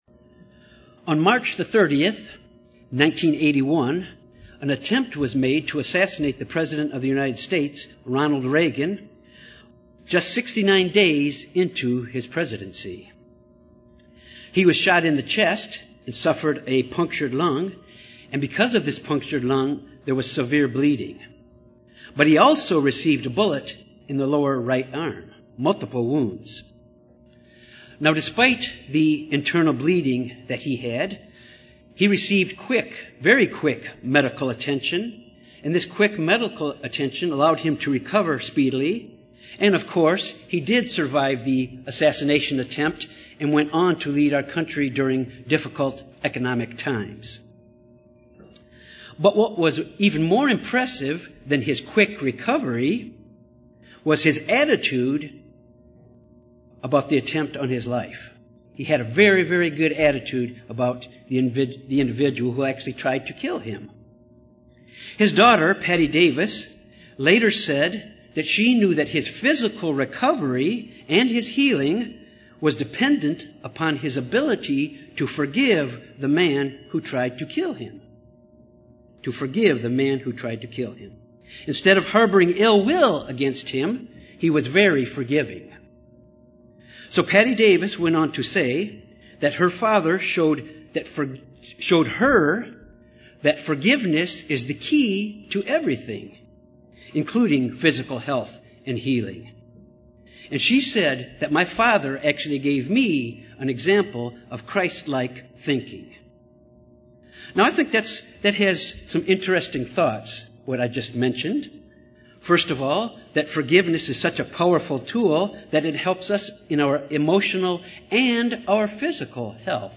Given in Little Rock, AR Jonesboro, AR
UCG Sermon Studying the bible?